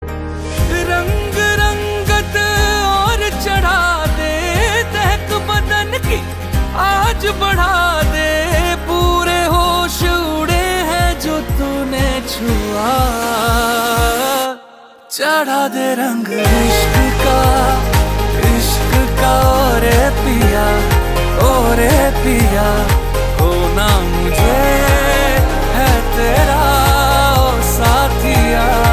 melodious tunes